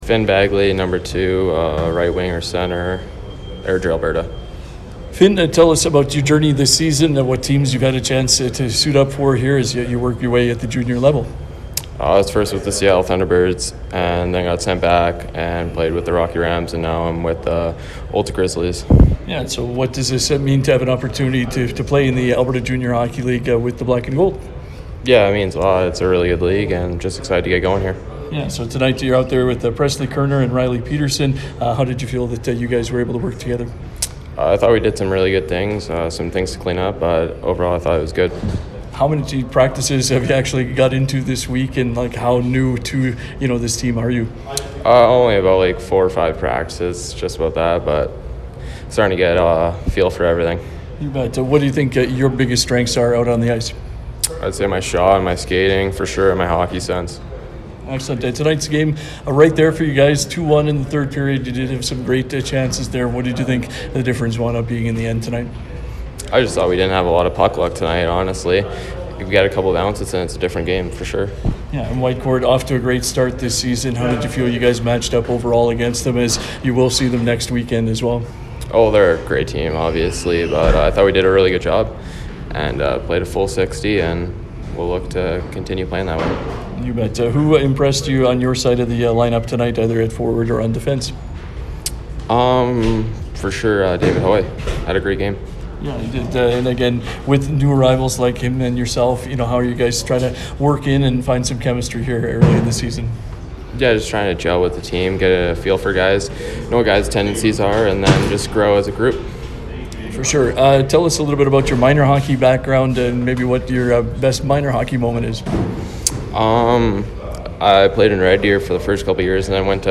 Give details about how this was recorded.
post-game audio